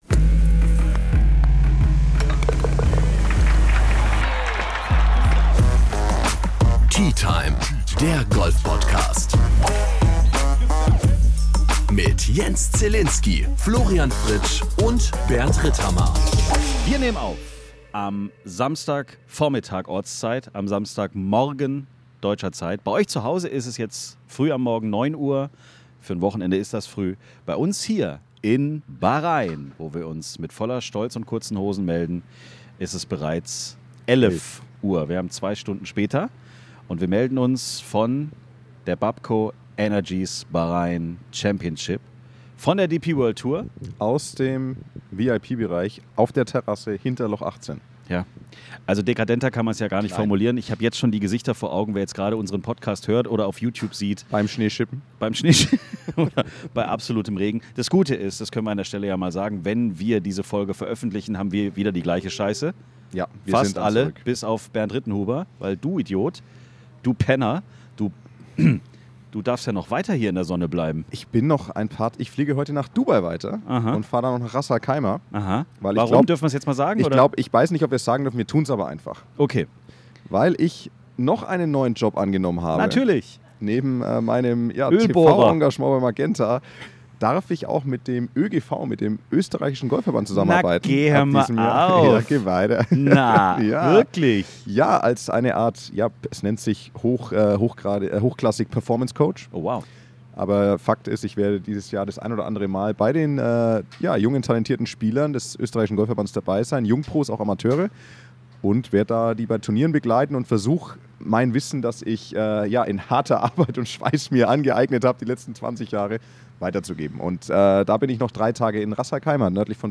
Mitten aus der Hospitality, vom Pro-Am, vom Sandgolfplatz und aus einer komplett anderen Golfwelt.